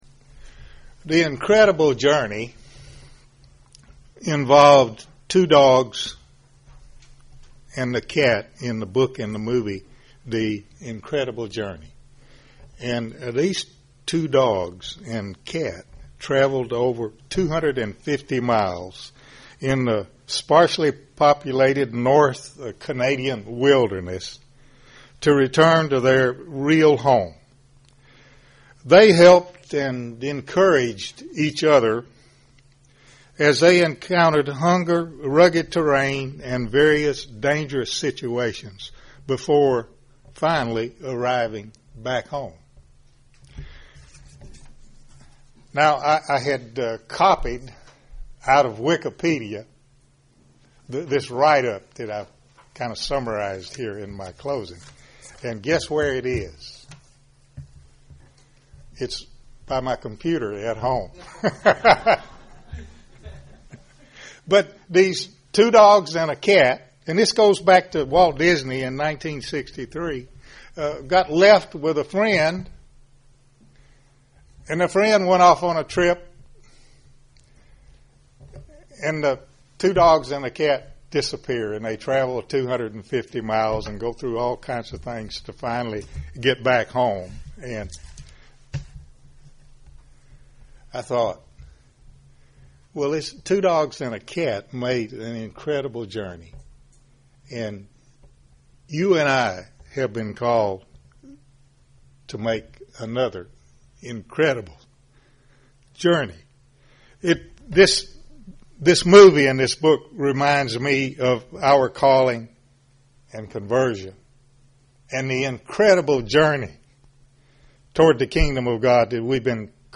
This sermon was given at the Gatlinburg, Tennessee 2013 Feast site.